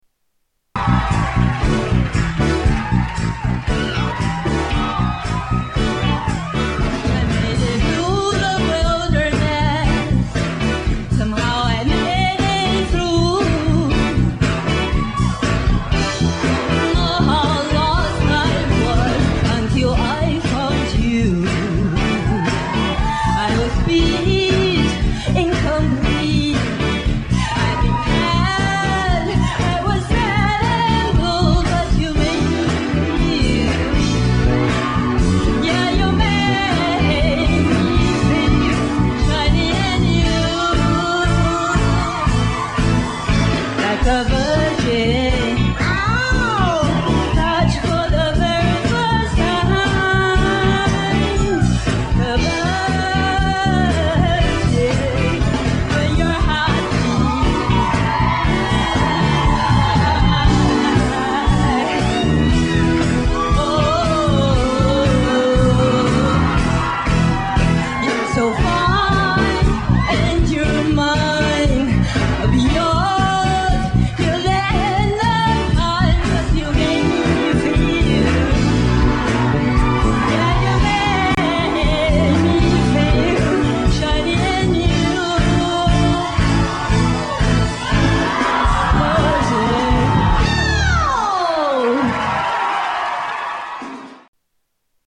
Category: Television   Right: Personal
Singers